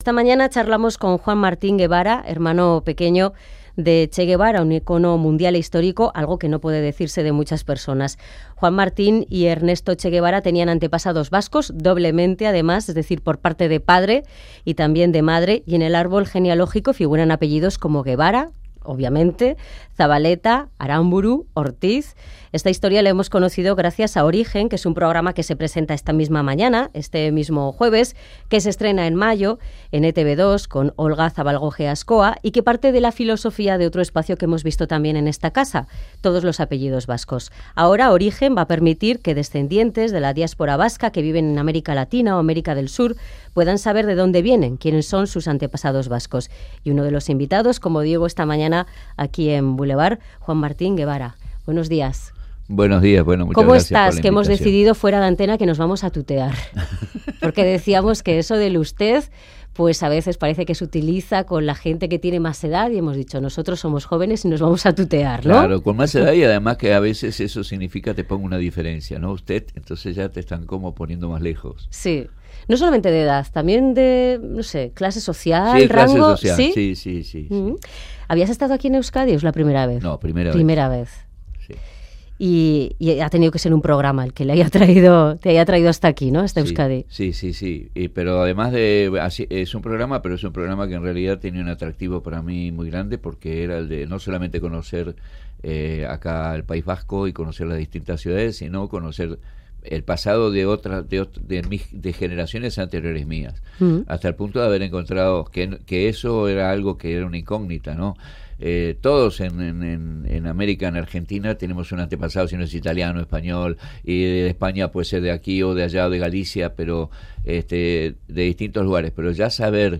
Antes charla con BVD sobre su vida y su hermano.